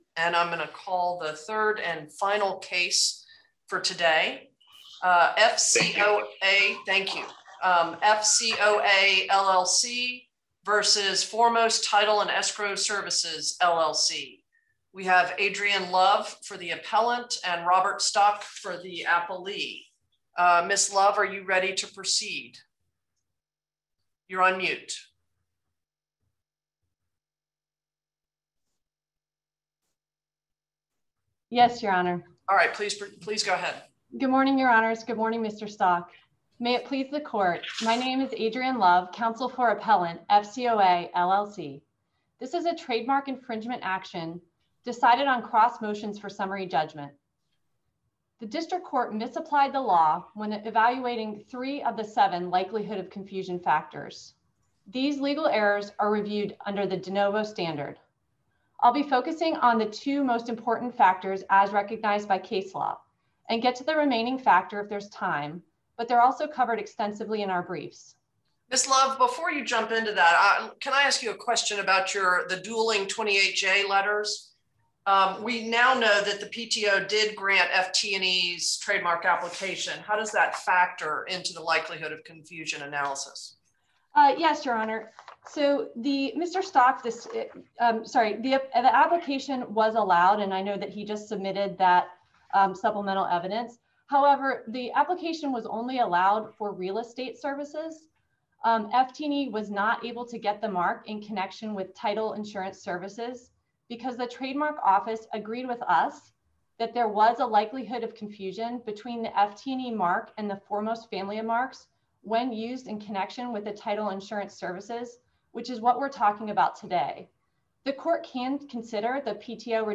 Oral Argument Recordings | Eleventh Circuit | United States Court of Appeals